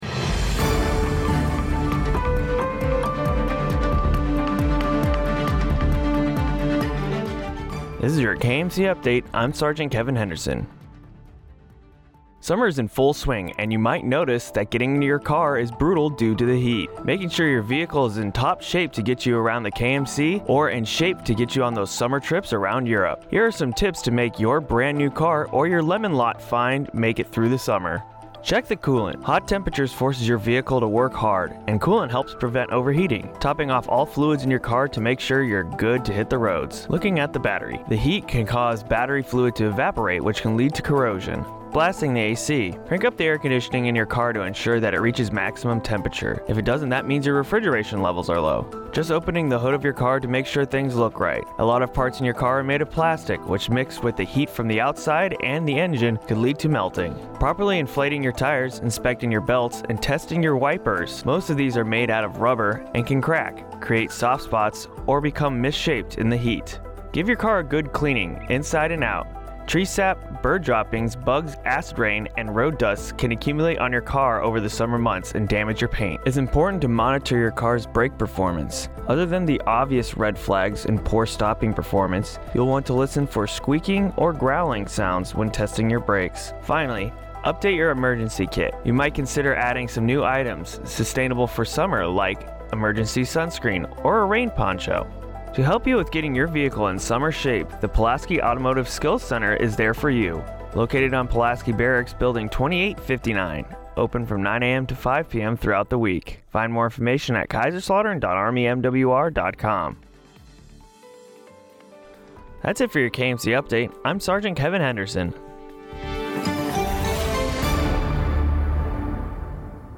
KMC News Update